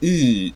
படிமம்:Close central unrounded vowel.ogg - தமிழர்விக்கி
Close_central_unrounded_vowel.ogg.mp3